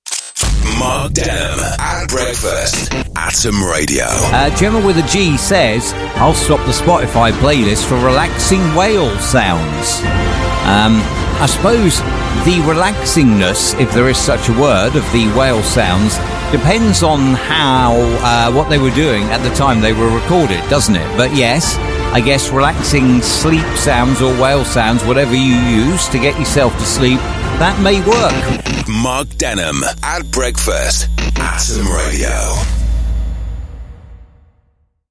Listeners from Slough, Windsor & Maidenhead share those subtle signals you shouldn’t ignore.